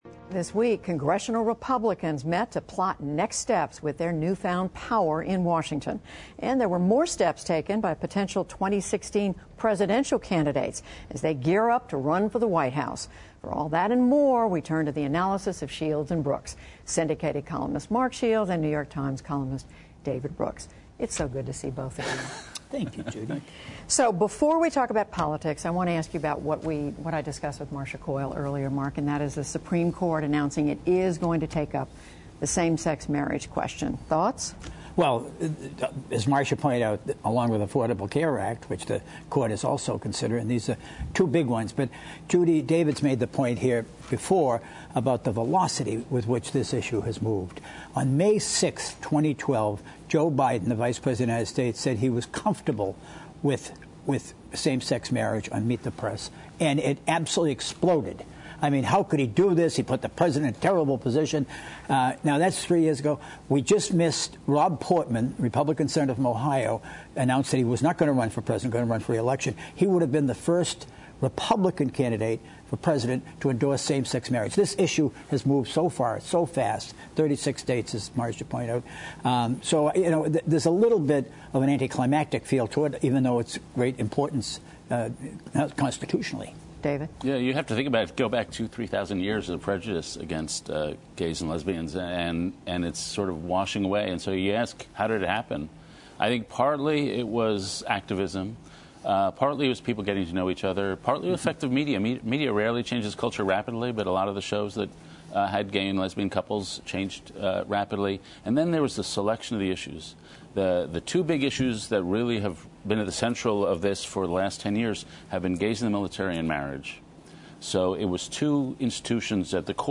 Syndicated columnist Mark Shields and New York Times columnist David Brooks join Judy Woodruff to discuss the week’s news, including the Supreme Court’s move to consider same-sex marriage, next steps for Republican congressional leaders, emerging GOP candidates for the next presidential race, plus thoughts on the NewsHour’s decision to not show the post-attack cover of Charlie Hebdo.